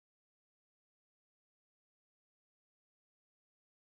noiseless.mp3